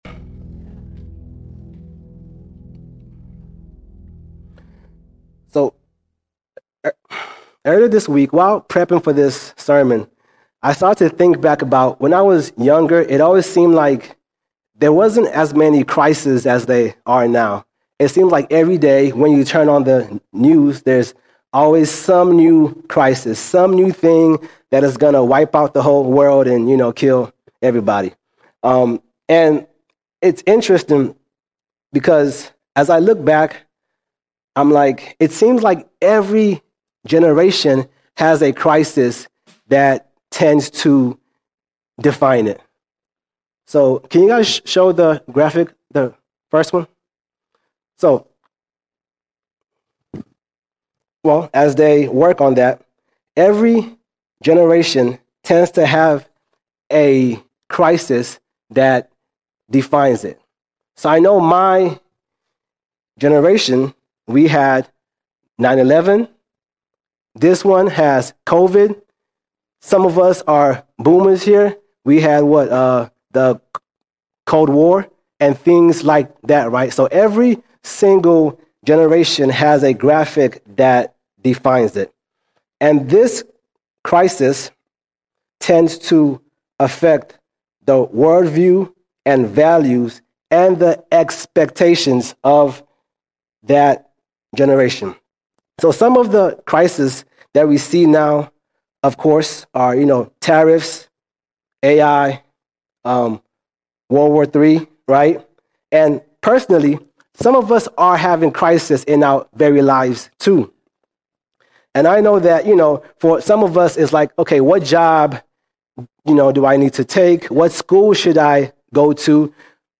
10 March 2025 Series: Sunday Sermons All Sermons Generational Crisis Generational Crisis The Greatest Crisis facing our generation is that light has come, but men refuse the light.